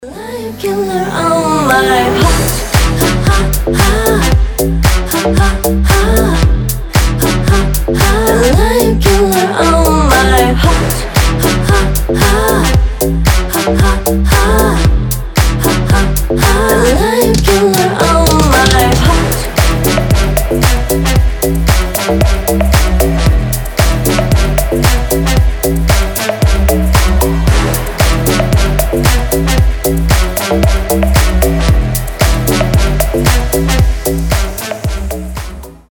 • Качество: 320, Stereo
поп
ритмичные
громкие
женский голос
мощные басы
house